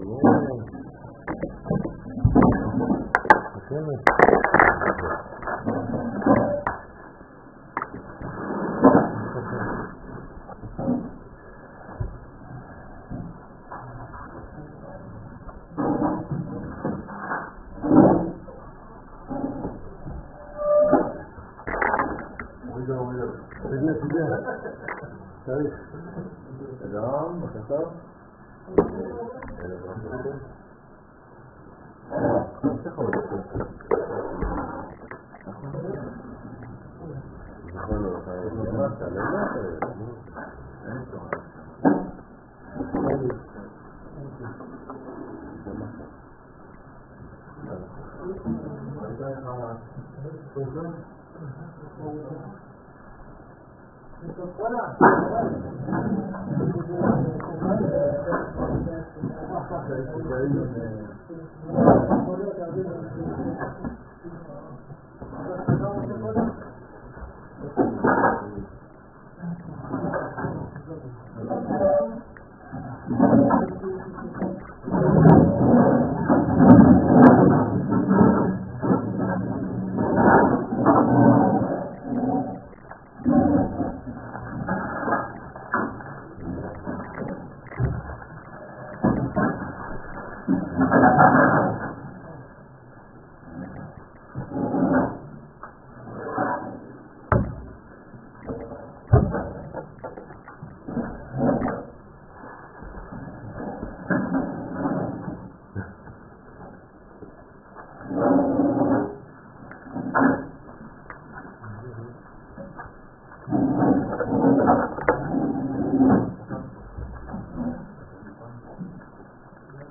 שיעור לילה יח אלול- חודש אלול
שיעור-לילה-יח-אלול--חודש-אלול.m4a